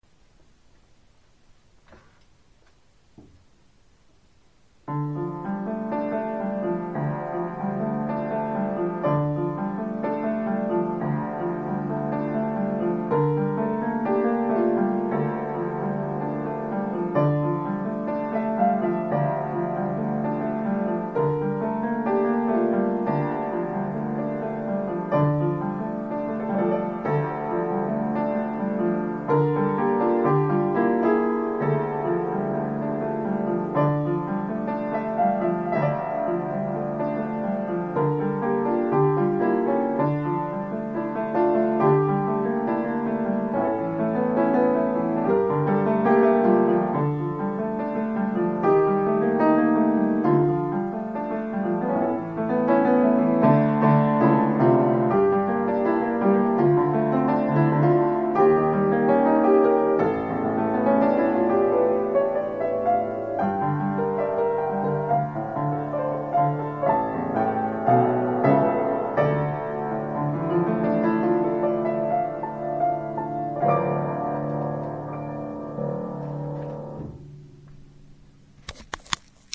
רוגע..או גלי ים..זה יותר כמו משו מפחיד,תחושה מציקה כזאת-שמשו הולך לקרות..משו סוער..
גם לי זה היה נשמע כמו כאלה גלים סוערים...